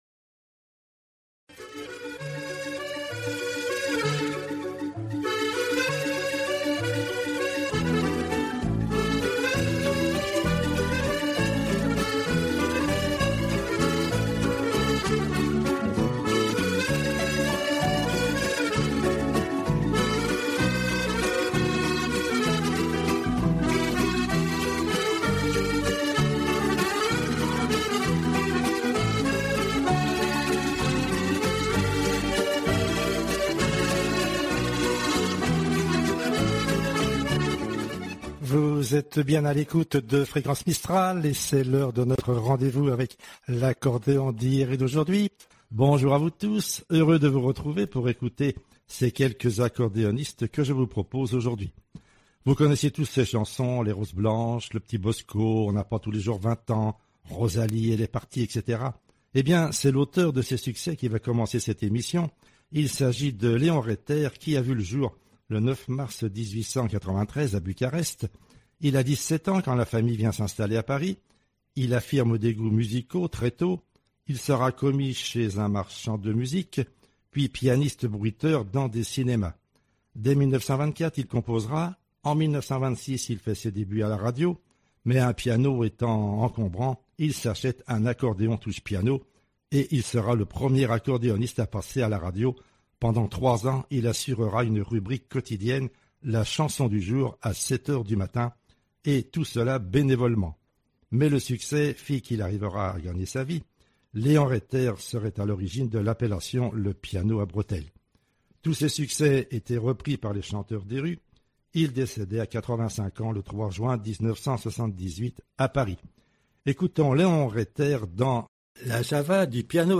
Accordéoniste anonyme
Accordéoniste finlandais